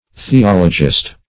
Theologist \The*ol"o*gist\, n.